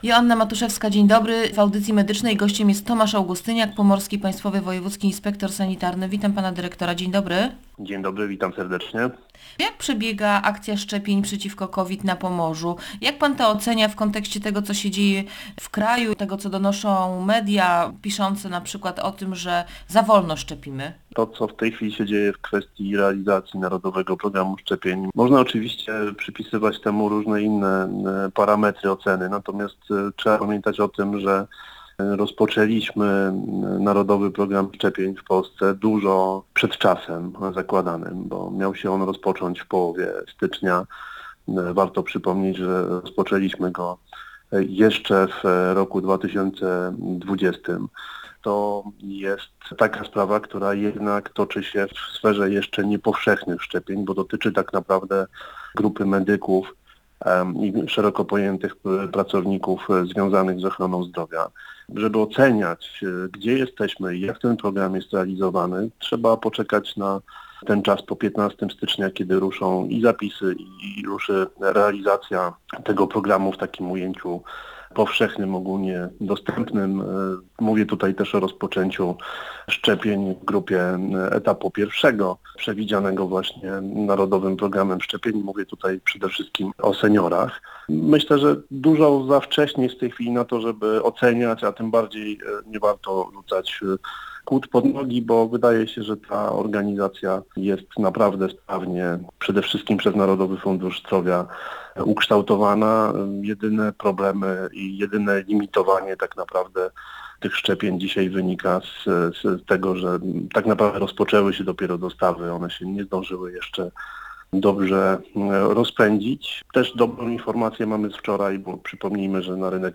Jak mówił w Radiu Gdańsk Tomasz Augustyniak, Pomorski Państwowy Wojewódzki Inspektor Sanitarny, wymazy będą pobrane od nauczyciele klas 1-3 i i pracownicy administracji placówek.